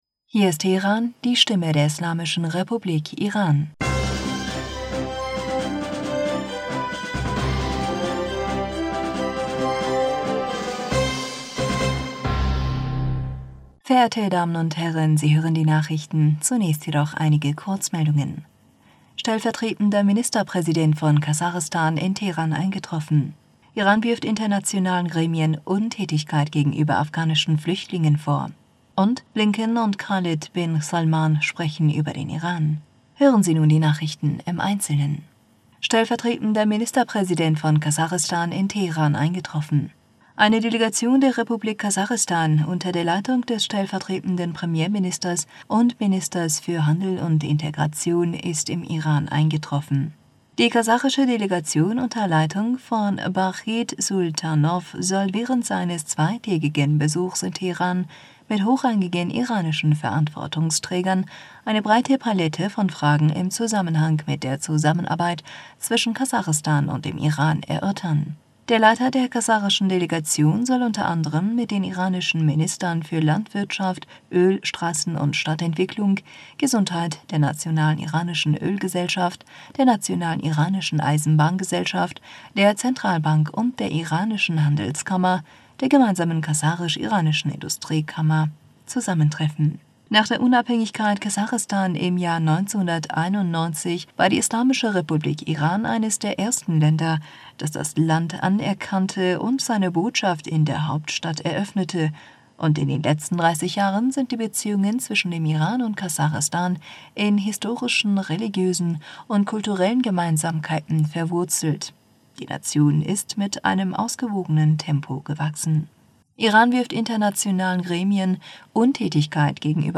Nachrichten vom 22. Mai 2022